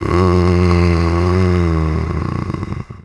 c_zombim4_atk3.wav